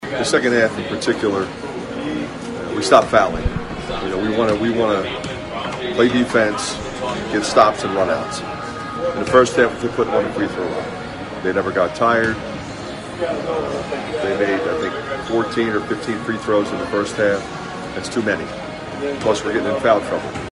That’s Iowa coach Fran McCaffery who says the defense was better in the second half.